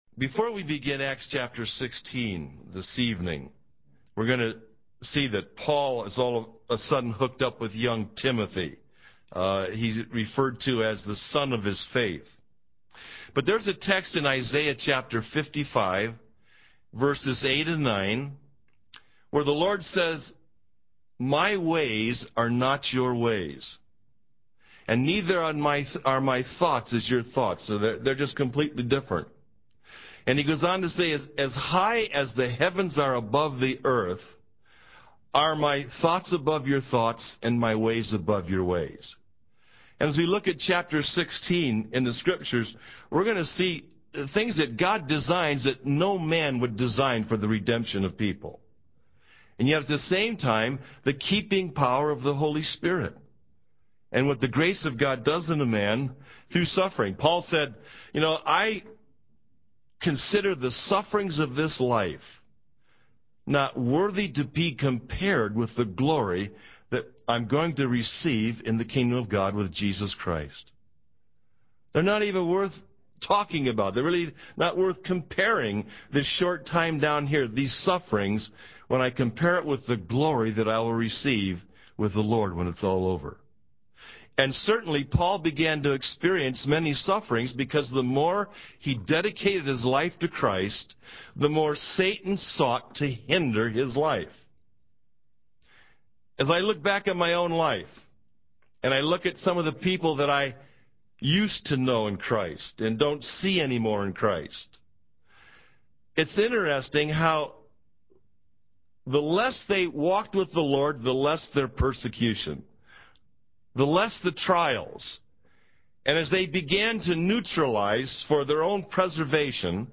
In this sermon, the speaker emphasizes the importance of sharing the word of God with others. He encourages the audience to see themselves as missionaries and to be open to opportunities to share their faith, even in unexpected places like gas stations or grocery stores. The speaker also highlights the story of Paul and Silas in prison, where their worship and praise to God led to the conversion of the Philippian jailer.